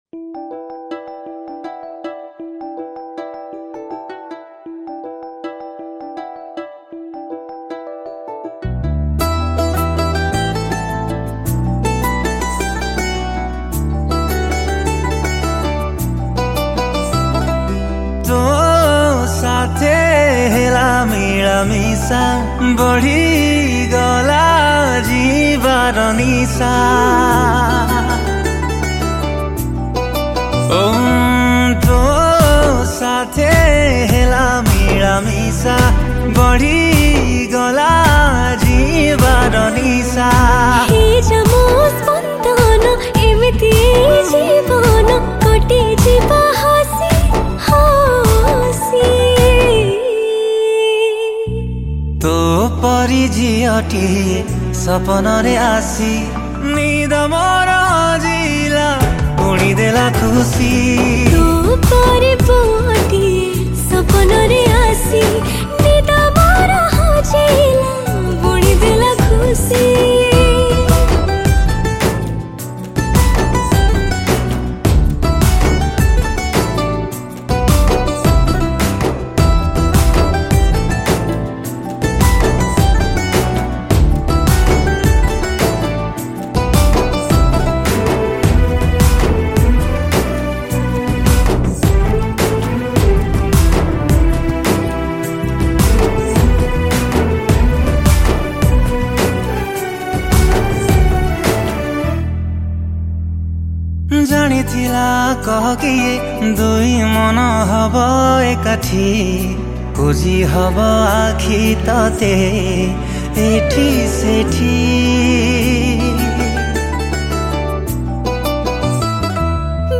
A beautiful Romantic Duet Song.